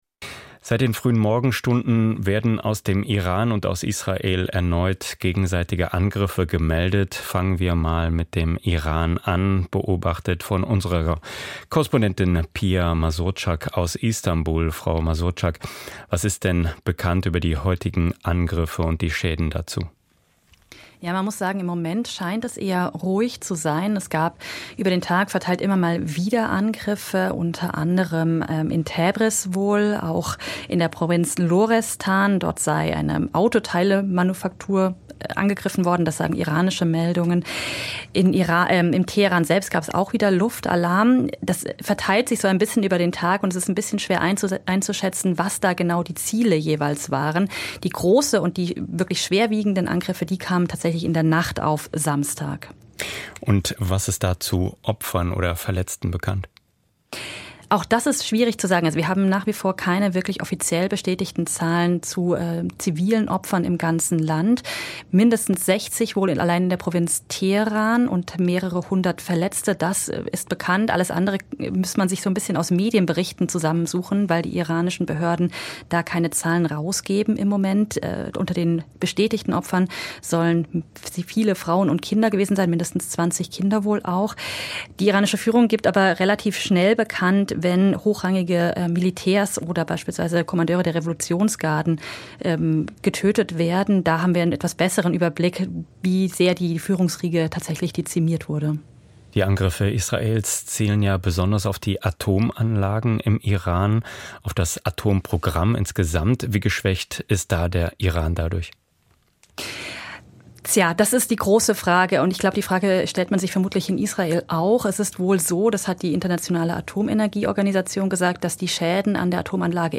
Hier finden Sie aktuelle Informationen, einordnende Berichte und hintergründige Gespräche mit Korrespondentinnen, Politikern und Expertinnen im In- und Ausland, ergänzt durch aktuelle Wirtschaftsberichte.